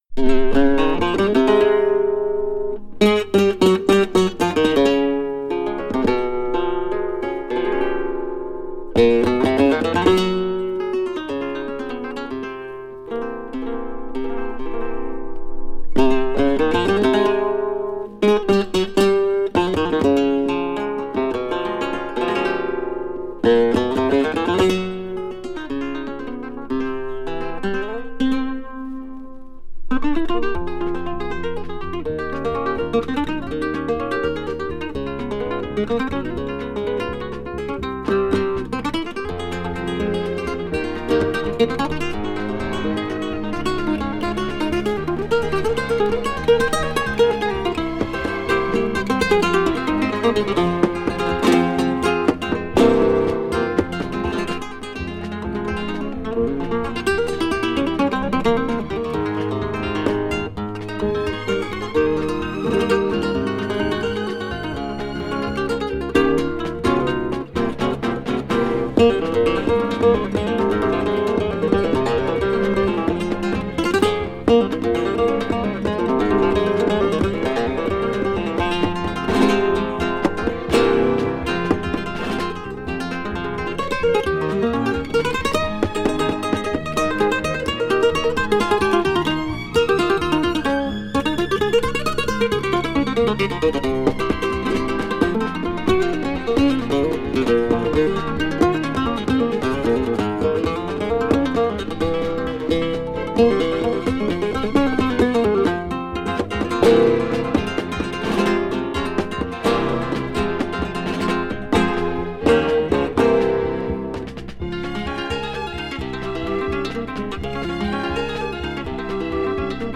bulerías